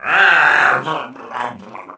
Wario growls and sputters underwater in Mario Kart Wii.
Wario_(Drown_2)_Mario_Kart_Wii.oga